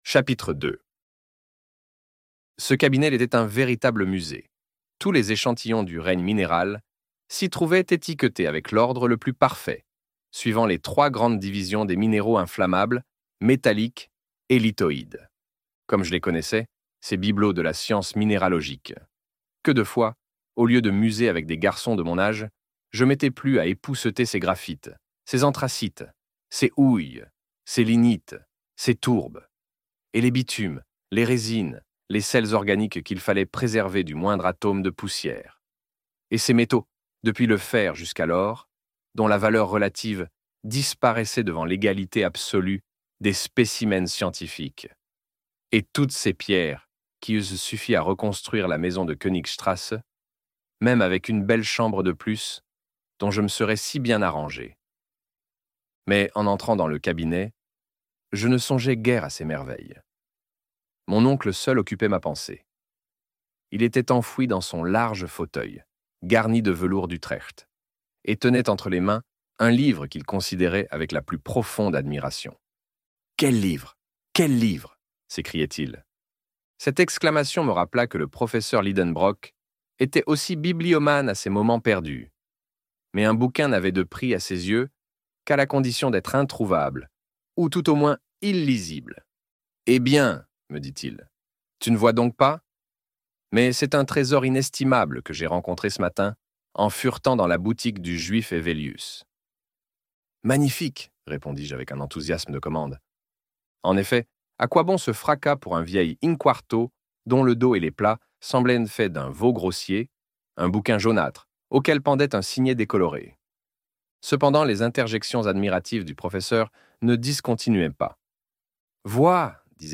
Voyage au centre de la Terre - Livre Audio